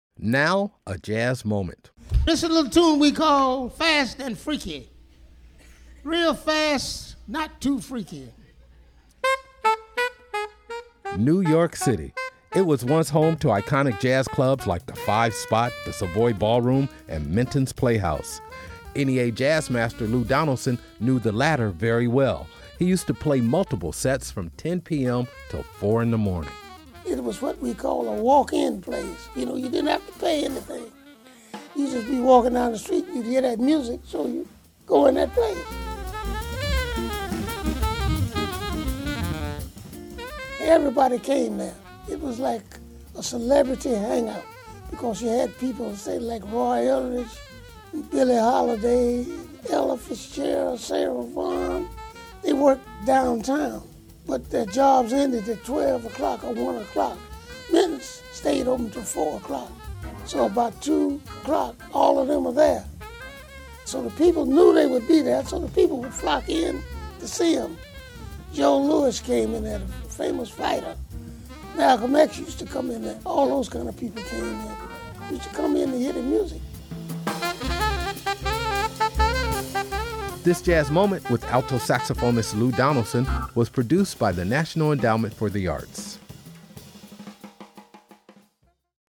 Excerpt from "Fast and Freaky” from the album, Live on the QE2, used courtesy of Chiaroscuro Records and used by permission of Tunemaker Music. (BMI)